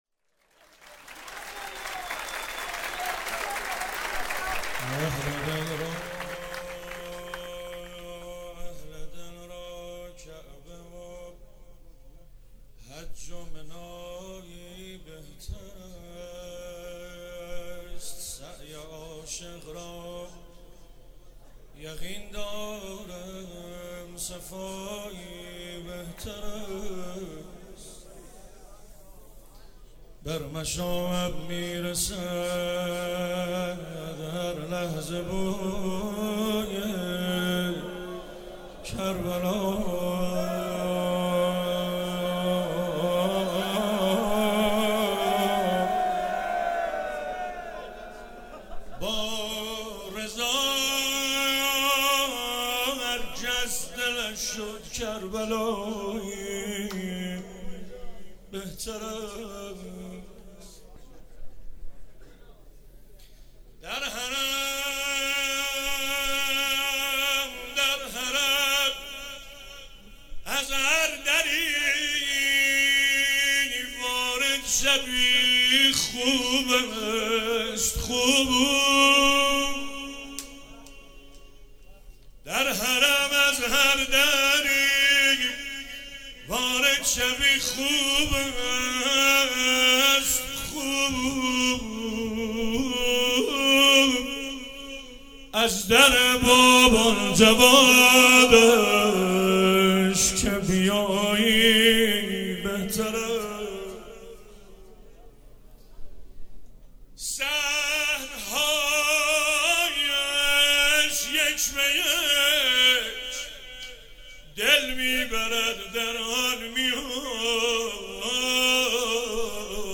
مراسم شب میلاد امام رضا(ع) 96
شعرخوانی